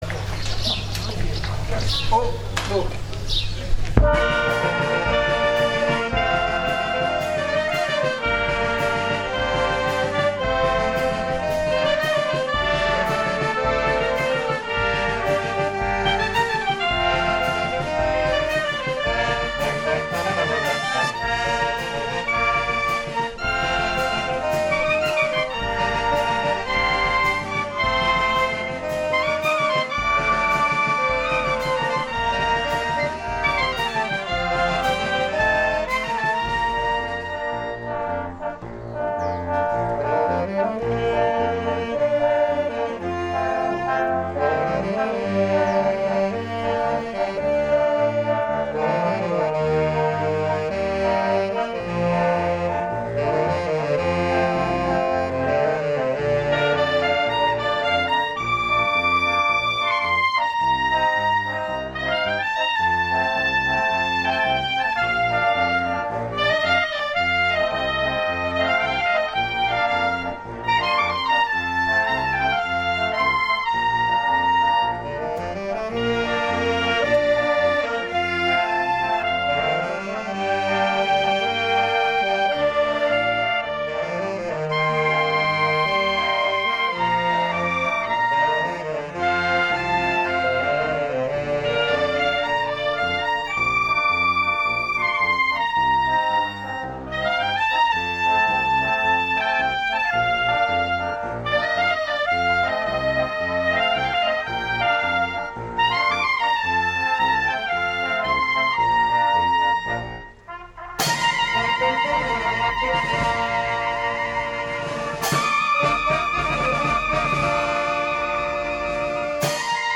ecco a seguire le quattro marce suonate prima della Messa e della processione che ne è seguita
Il trovarsi in prossimità della banda nella quale suonano anche parenti stretti del vostro cronista però, ha fatto sì che per conflitto d’interesse del quale approfittare, (nonostante mancassero i dispositivi portatili di controllo ed aggiustamento delle audio-registrazioni), si procedesse comunque a registrare alla ‘come vié vié’ ed i risultati sono quelli che si possono ascoltare cliccando gli audio proposti.